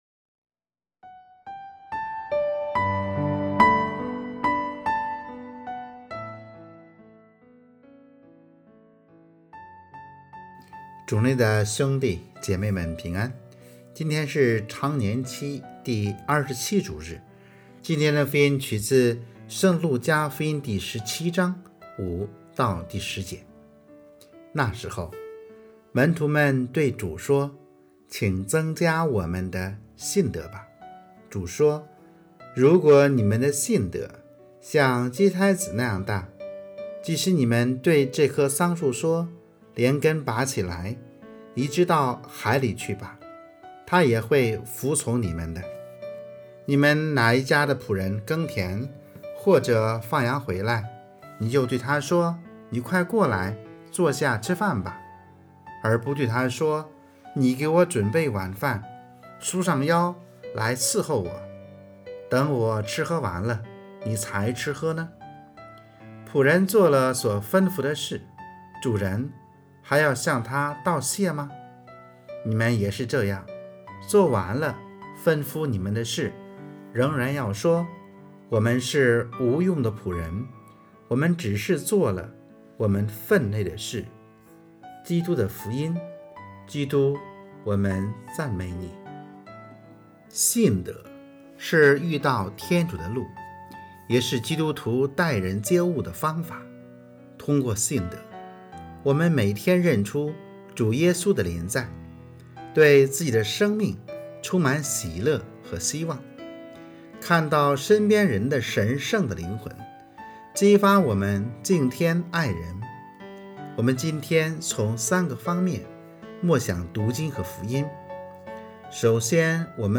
【主日证道】|信德就是新生活（丙-常27主日）